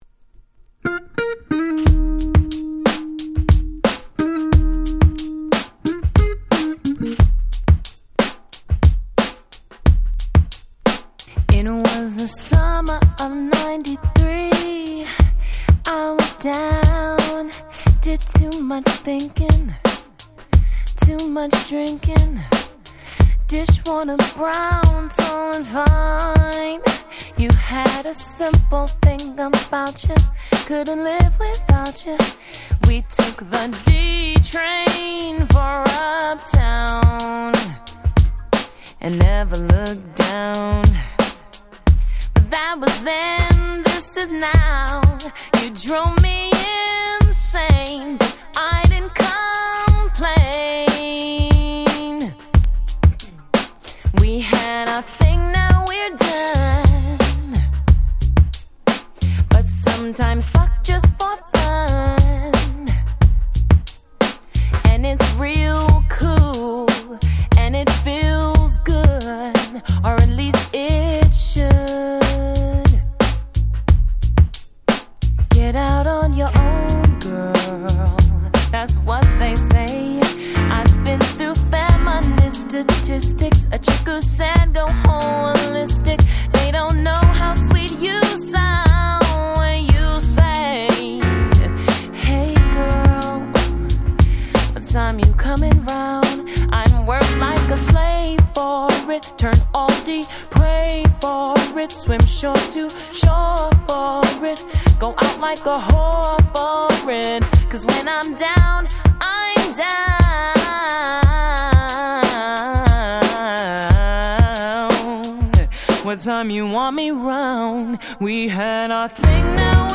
98' Nice Acid Jazz !!
ムーディーでメロウなSmooth Soul !!